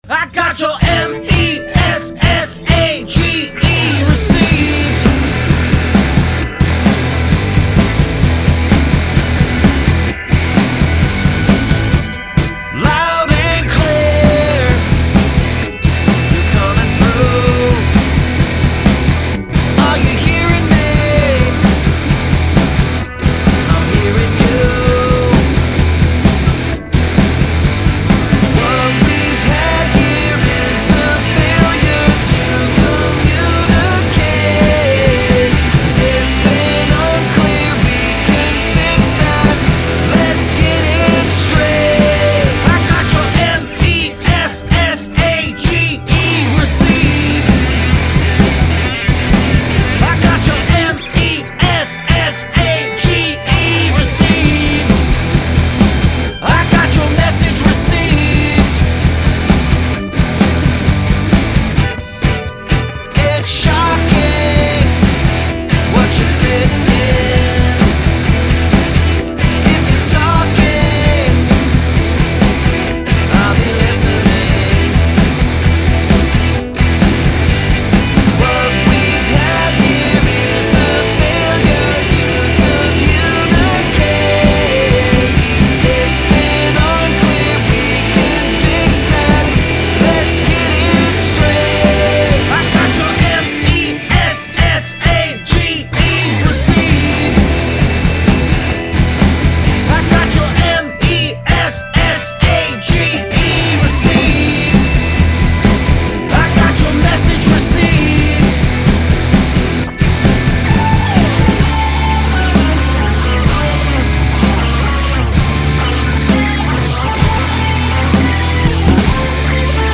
Use of spelling in a song.